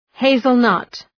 Προφορά
{‘heızəl,nʌt}